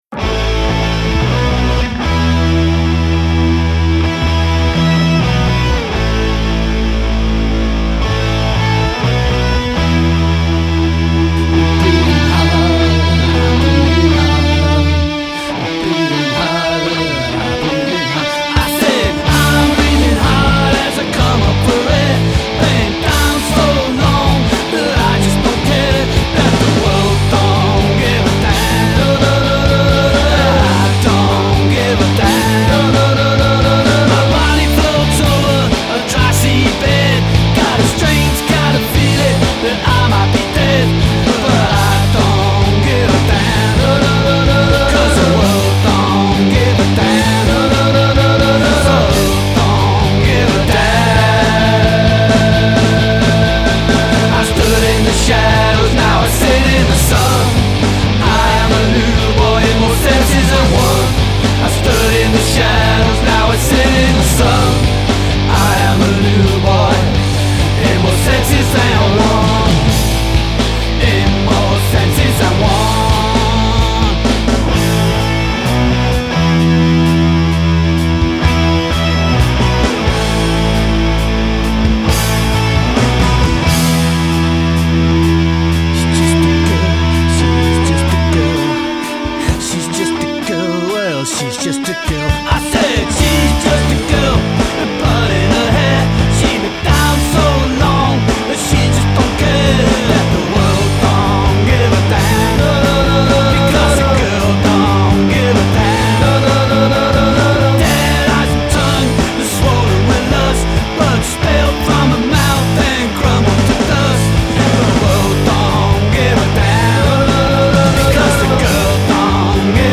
That's why the middle part of the song sort of gets ethereal and other worldly sounding, well that's the atmosphere we were trying to get.
Hitchcock Moments: I sing backing vocals on this track and play some 12-string guitar in the middle section.